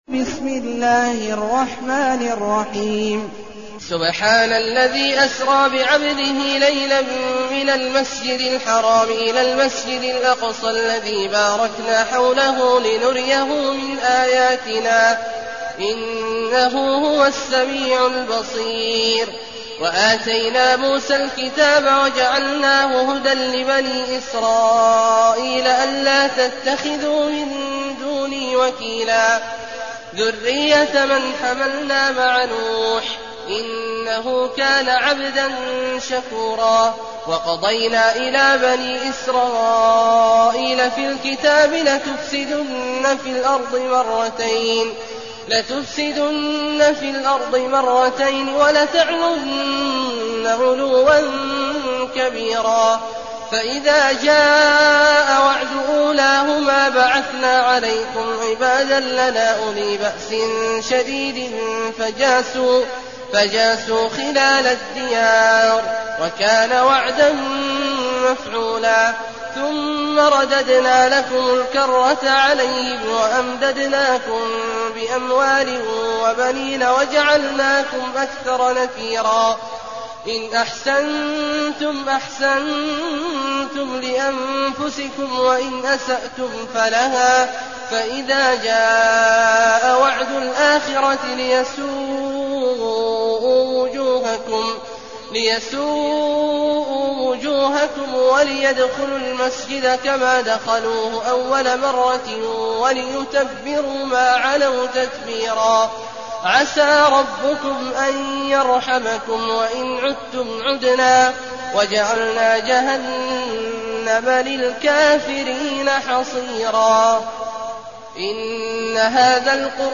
المكان: المسجد النبوي الشيخ: فضيلة الشيخ عبدالله الجهني فضيلة الشيخ عبدالله الجهني الإسراء